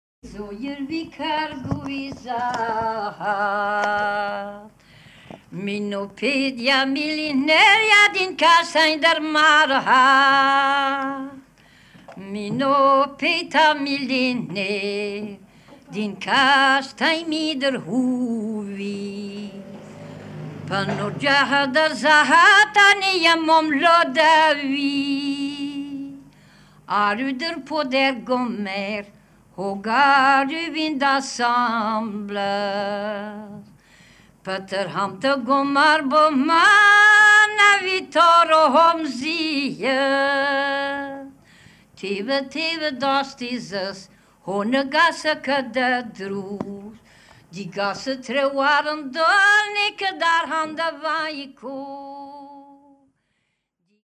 traditional songs
Wild unique kitchen sound recording!
The common points between all these recording is that most of them were made on private equipment (and even later on tape recorder) and also most of them were recorded in the kitchen.
Then although most of fireplaces had disappeared, been replaced by ceramics on the walls (hence the unique sound of kitchen recordings!) and electrical stove, it is still the place you get to discuss, drink bowls of coffee (no, no mug or cup, only bowls!), eat pancakes and butter cakes…